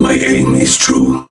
robo_bo_ulti_vo_02.ogg